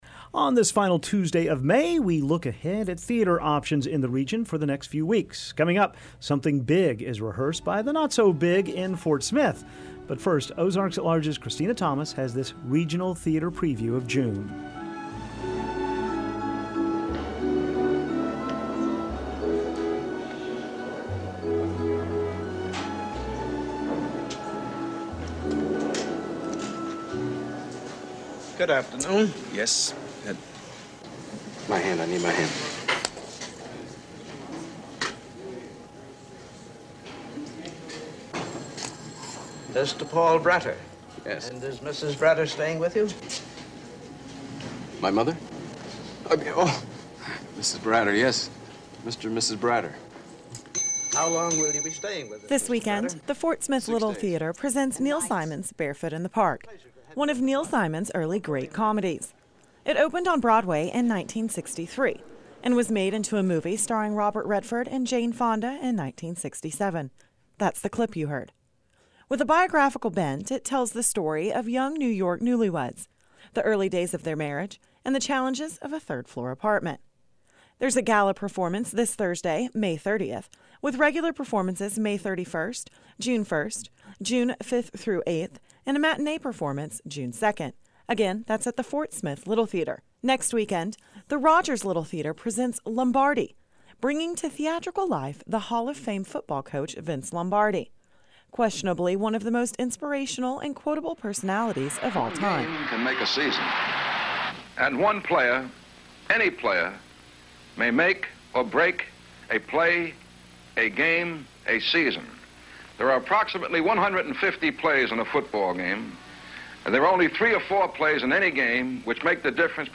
visits the Young actors Guild in Fort Smith as they rehearse BIG: The Musical.